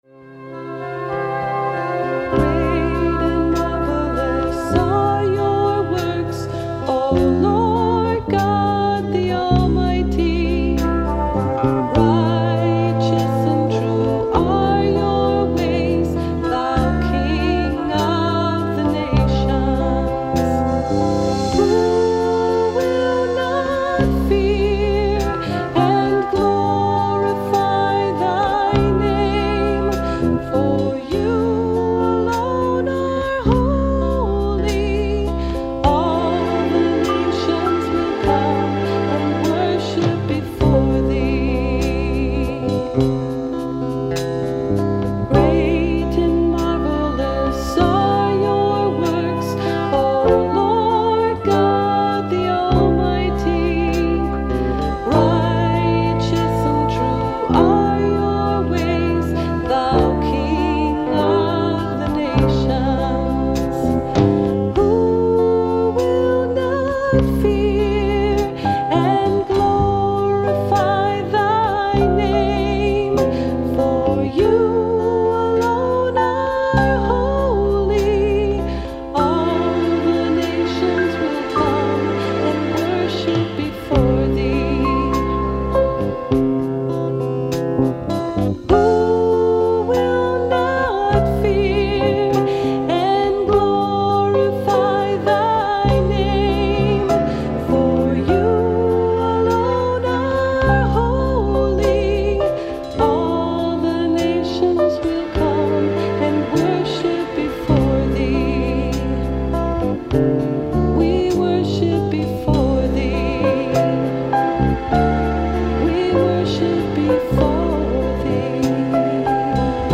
[Karaoke Video with vocal]